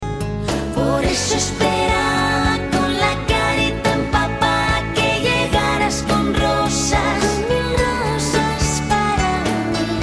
A beautiful Spanish pop-rock ballad